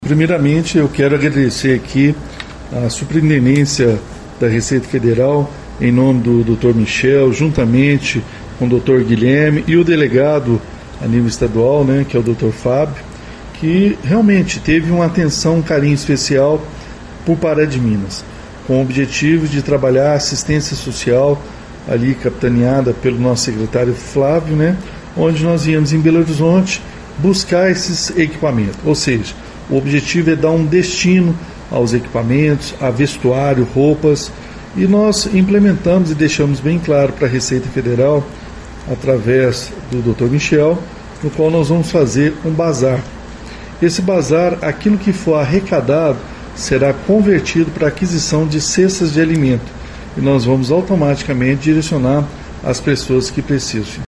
Segundo o prefeito Elias Diniz, os telefones serão aproveitados internamente e o restante será vendido em um bazar para a compra de cestas básicas. O chefe do executivo municipal ainda destaca em quais áreas serão destinados os produtos:
Clique e ouça Elias Diniz